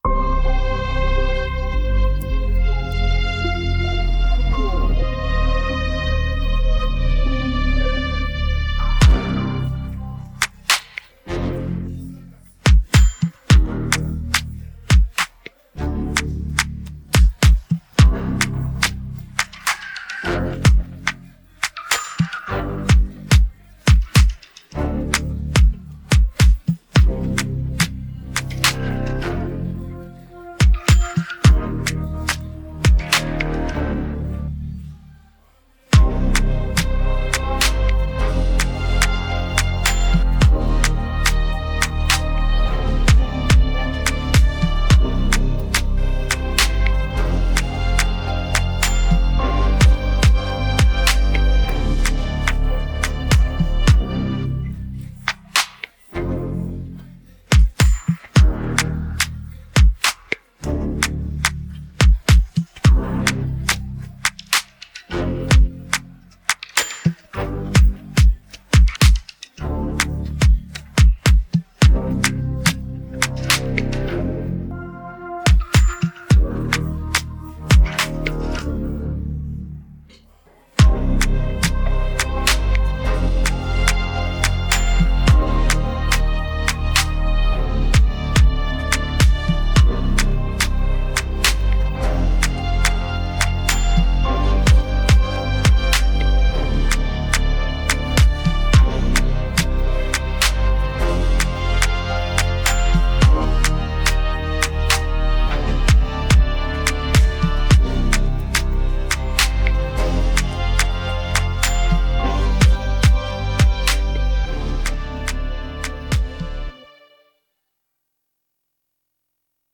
AfrobeatsDancehall